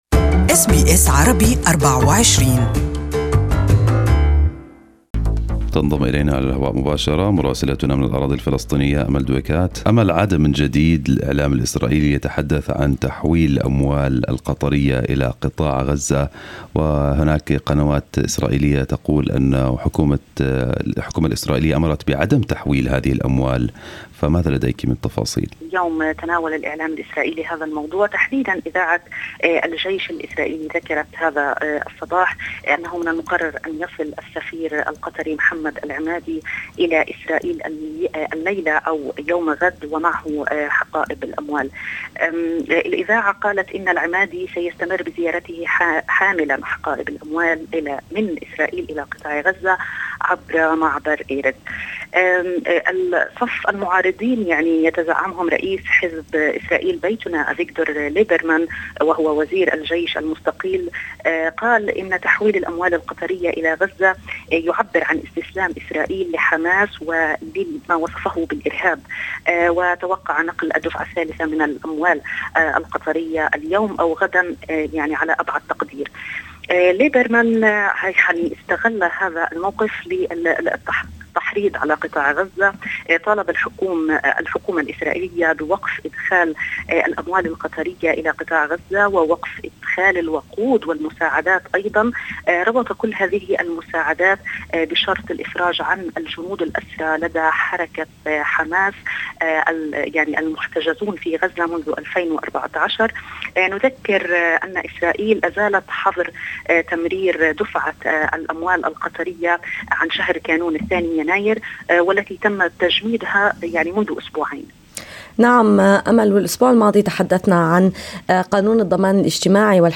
Details with our correspondent from Ramallah